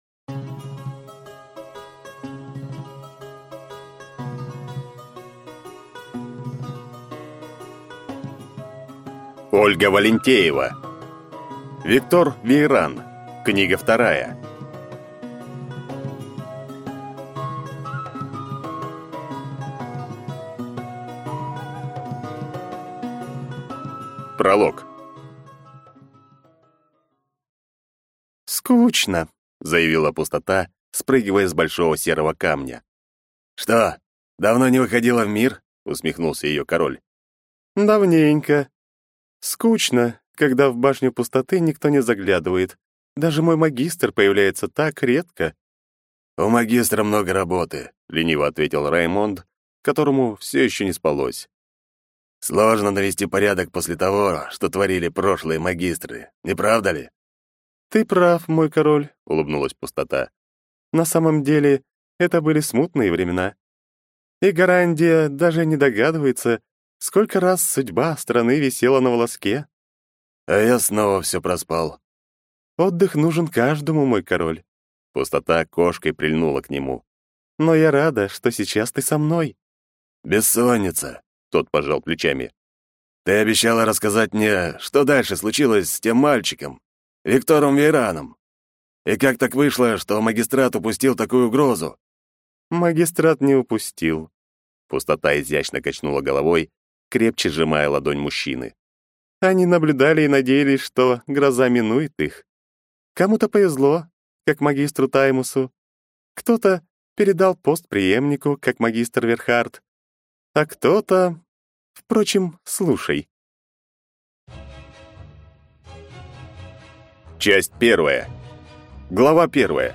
Аудиокнига Виктор Вейран | Библиотека аудиокниг